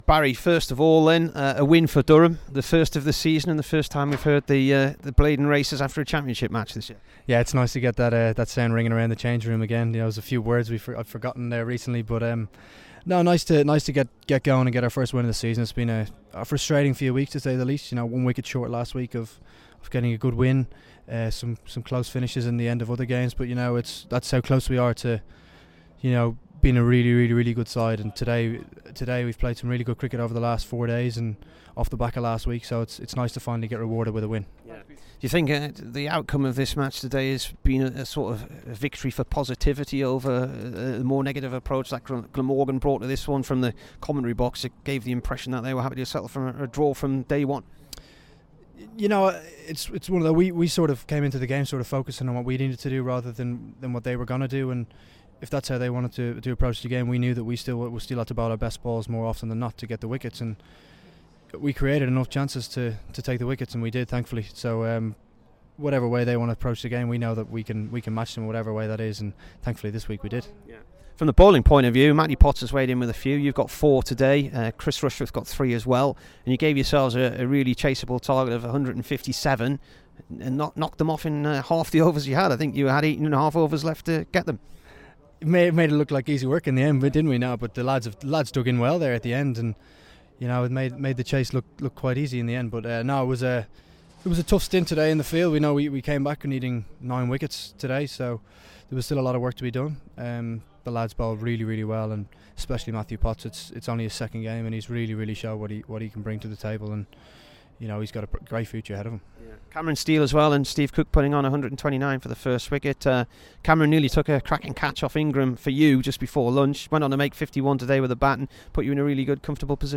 BARRY MCCARTHY INT
HERE'S THE DURHAM BOWLER AFTER HE TOOK 4-65 AND EIGHT WICKETS IN THE GAME V GLAMORGAN TO SECURE DURHAM'S FIRST CHAMPIONSHIP WIN OF THE SEASON.